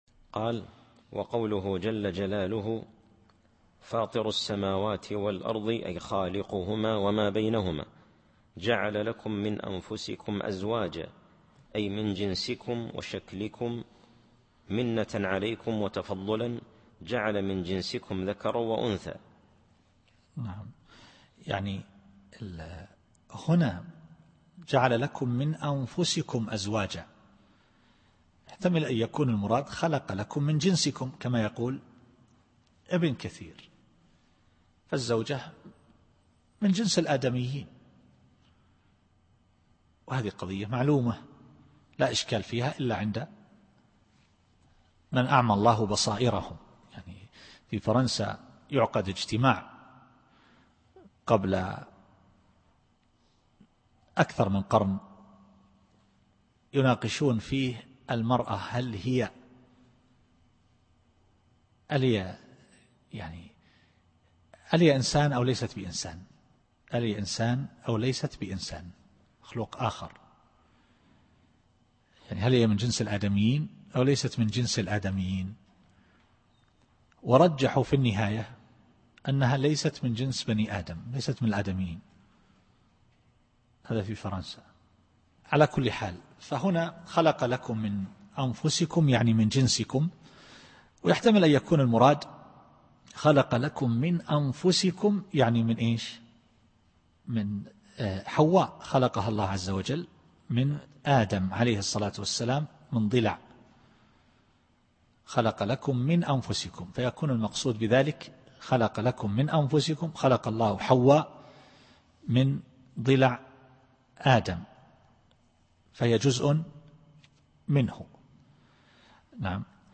التفسير الصوتي [الشورى / 11]